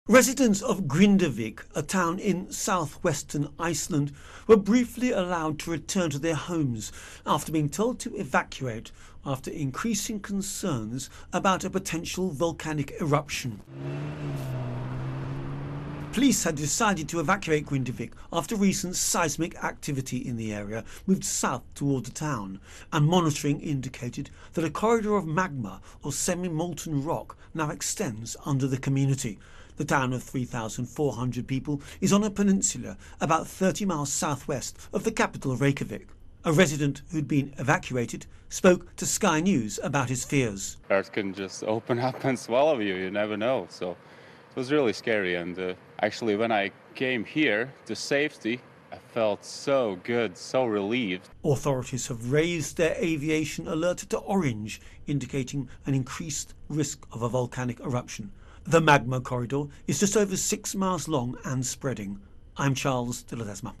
reports on Iceland Volcano.